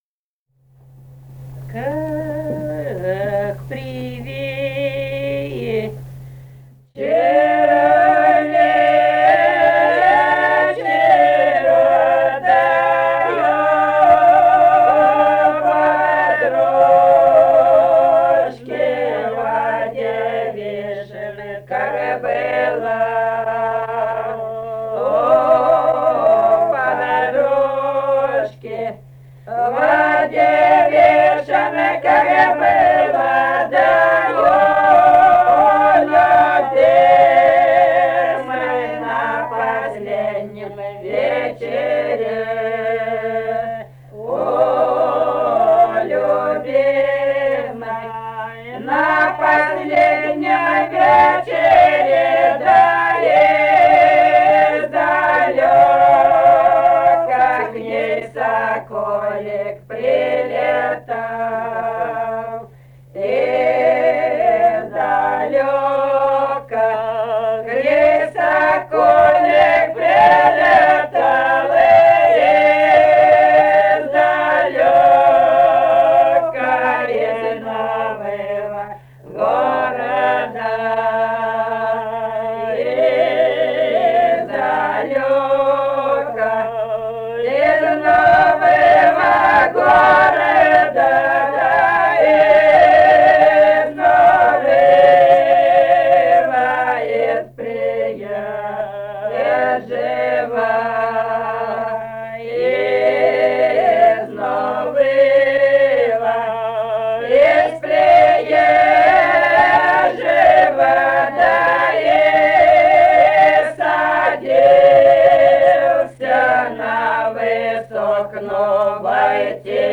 полевые материалы
«Как при вечере, вечеру» (свадебная).
Самарская область, с. Усманка Борского района, 1972 г. И1317-17